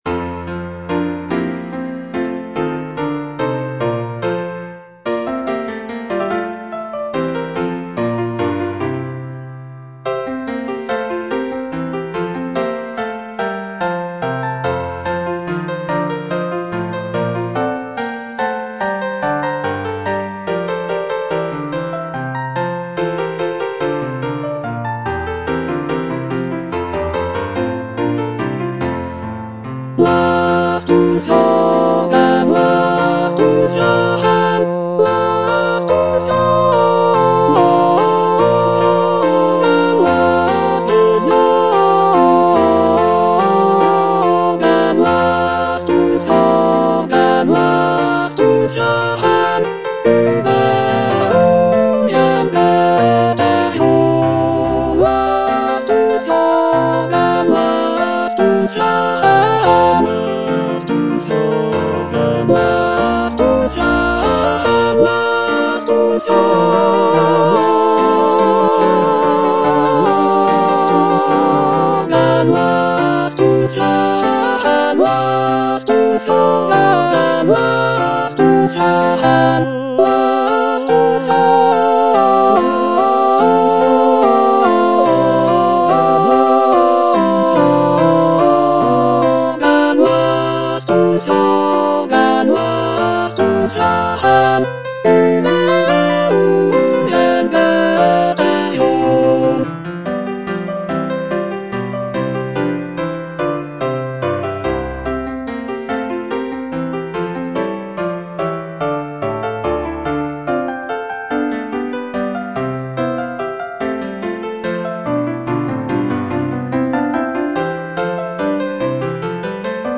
The cantata features a celebratory and joyful tone, with arias and recitatives praising the prince and his virtues. It is scored for soloists, choir, and orchestra, and showcases Bach's mastery of counterpoint and vocal writing.